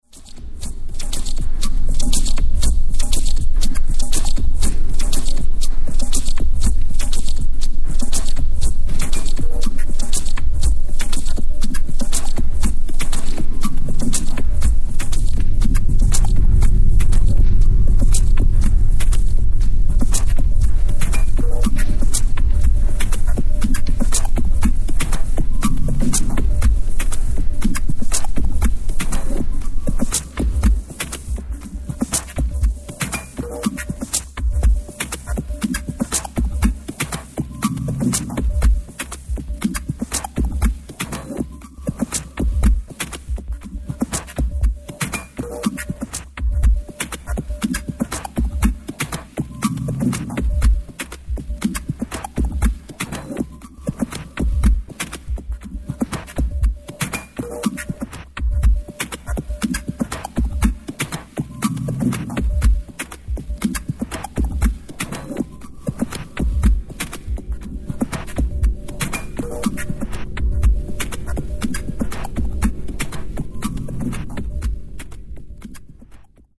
フロアー要素ほとんど無しのアンビエントやノイズやダブやミニマルが延々10曲続く廃人リスニングエレクトニックサウンド。
ambient/noise/cub/minimal